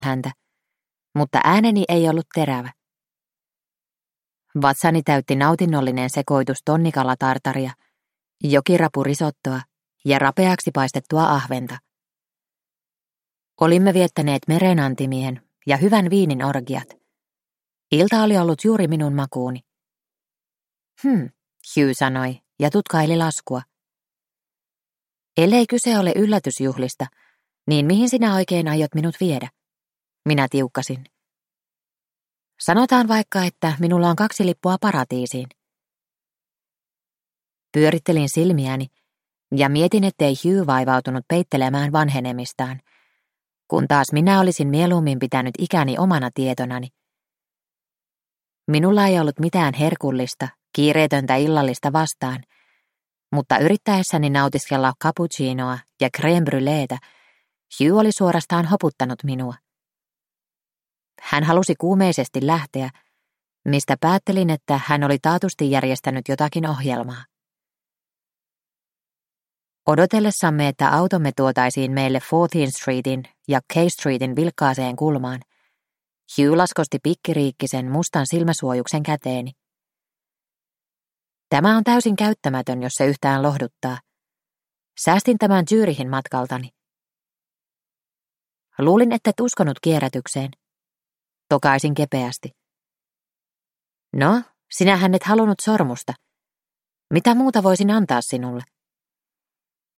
Rei Shimura taifuunin silmässä (ljudbok) av Sujata Massey